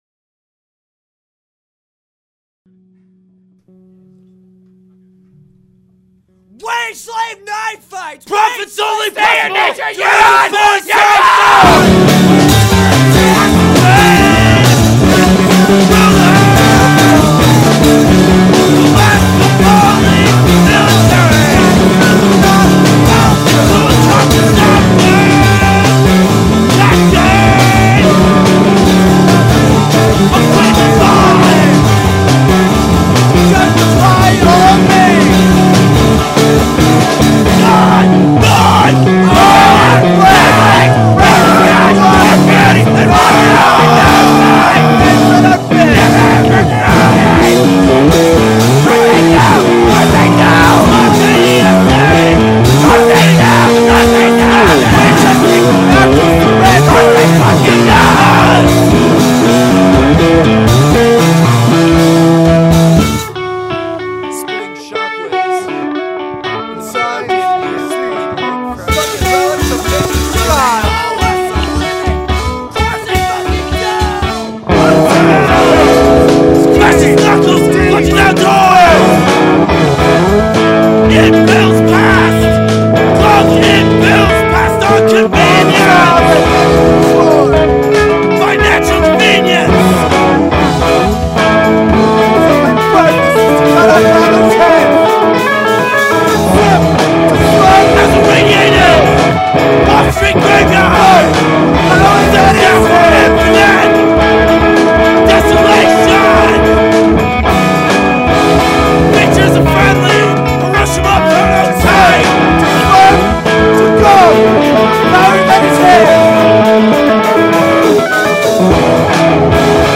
at Naropa